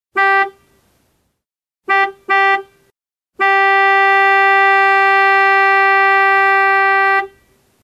Звуки нивы
На этой странице собраны характерные звуки автомобиля Нива: рев двигателя, сигнал, скрип дверей и другие.